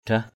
/d̪rah/